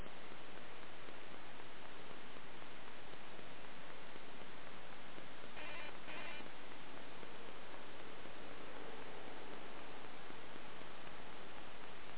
I have four RCA VR5220-A audio recorders and all four display the same audio anomalies when I record in quiet environments. There is a continuous ticking noise and then what can only be described as a digital quack that happens every couple of minutes. I’ve attached an mp3 example of the ticking and quack. These are not sounds present when the recording was made.